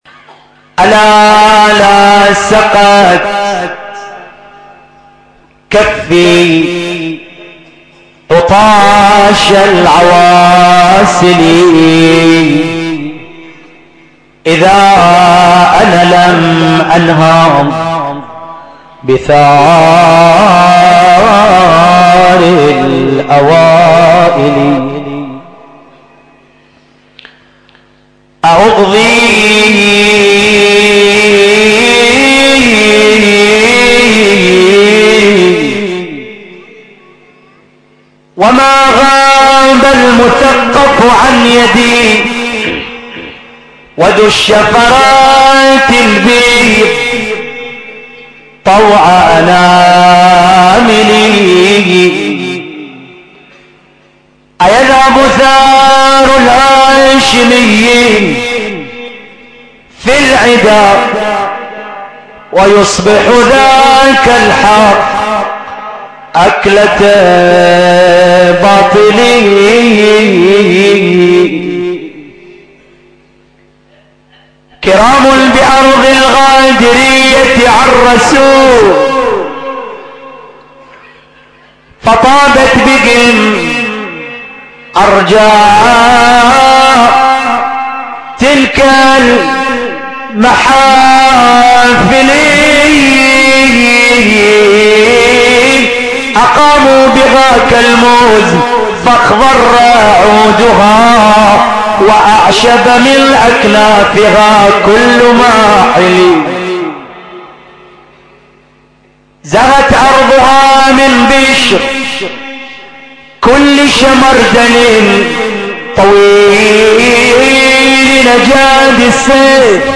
أبيات حسينية – الليلة الثامنة من محرم